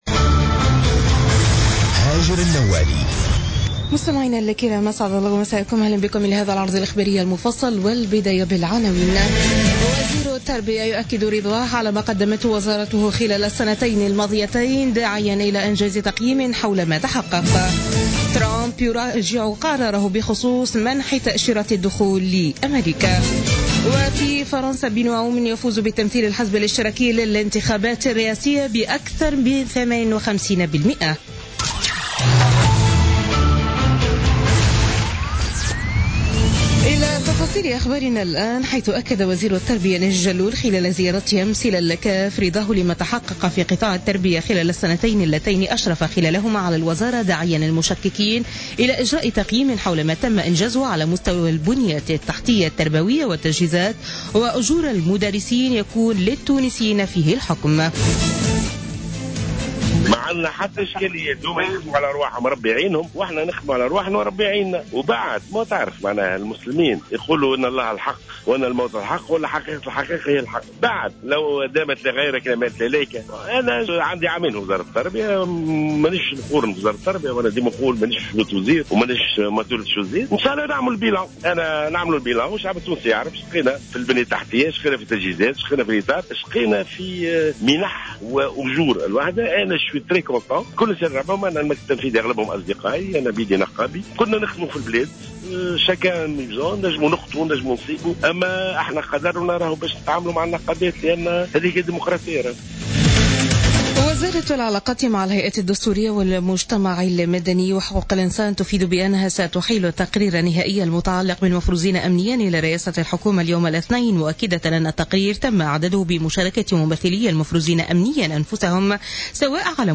نشرة أخبار منتصف الليل ليوم الإثنين 30 جانفي 2017